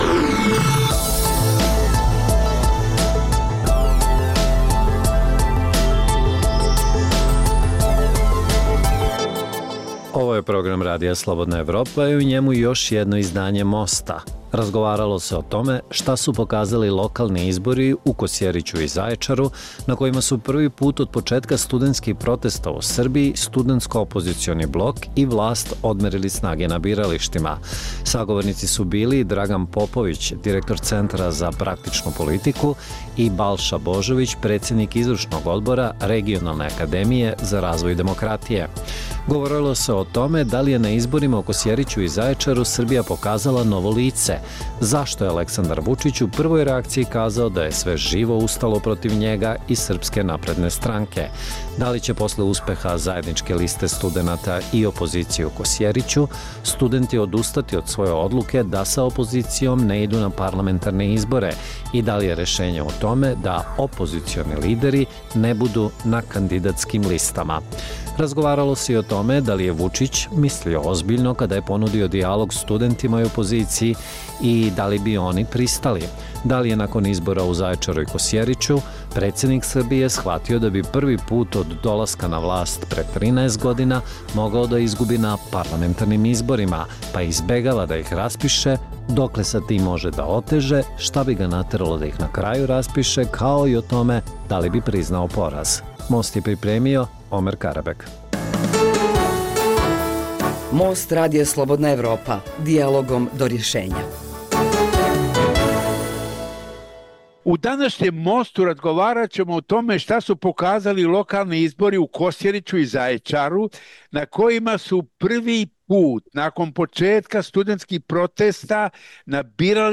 Dijaloška emisija o politici, ekonomiji i kulturi.